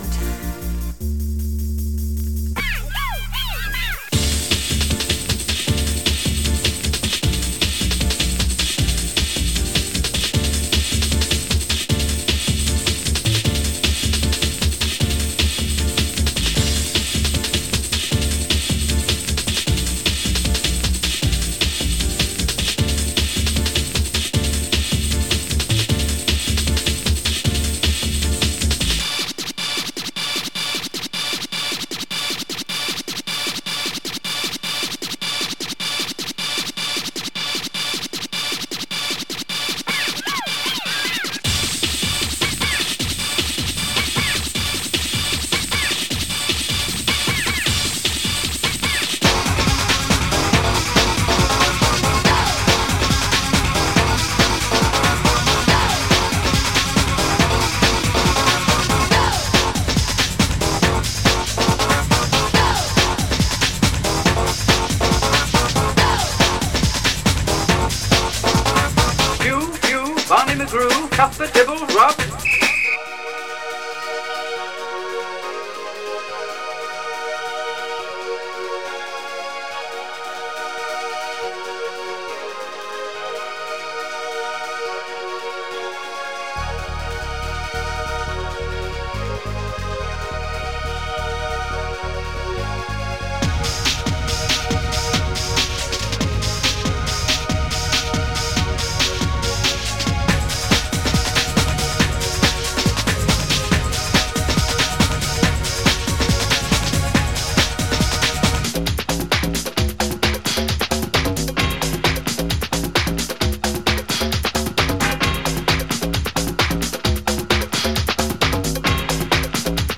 Lots of surface marks and scratches some light noise